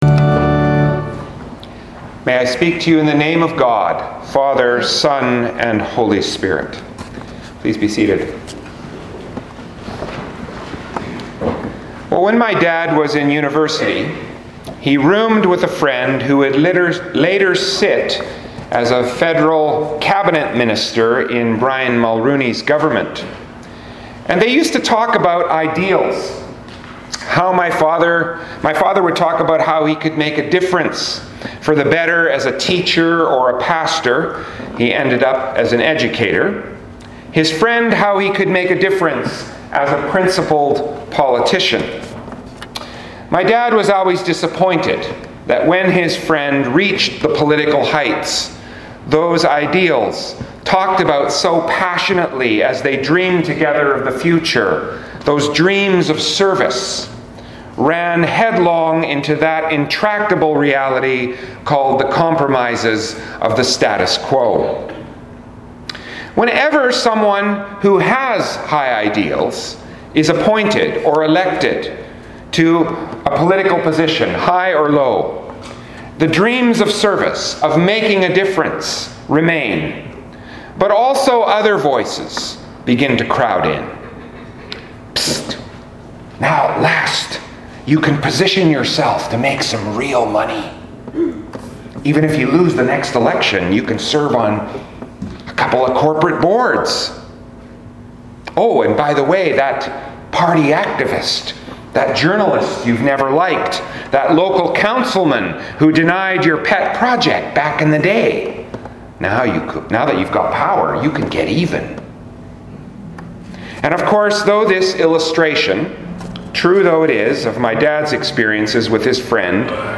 Sermons | St. Thomas Anglican Church